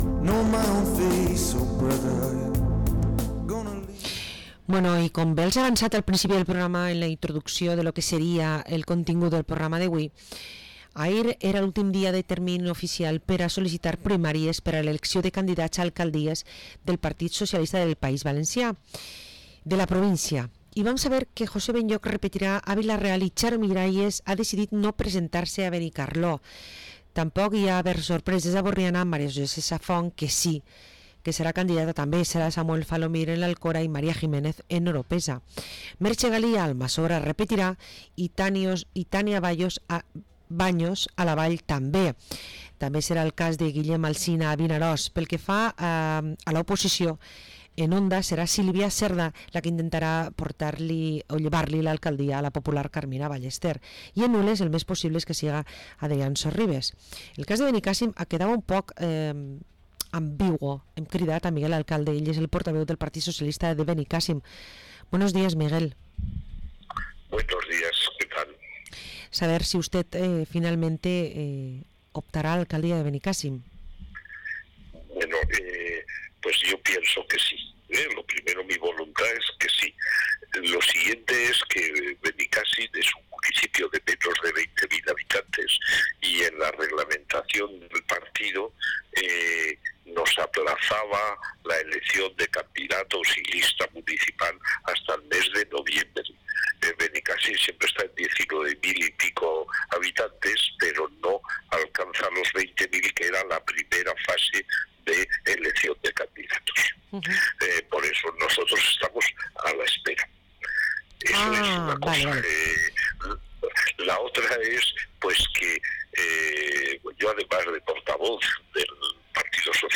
Entrevista a Miguel Alcalde, Portaveu Municipal Socialista en l’Ajuntamiento de Benicàssim